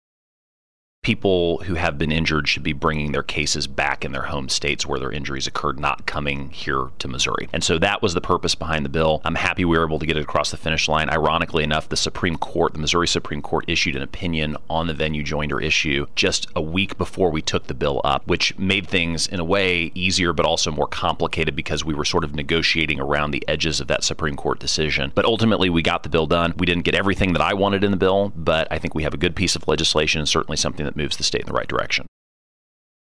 4. Senator Luetkemeyer says Senate Bill 7 is legislation that would modify provisions of civil procedure regarding joinder and venue.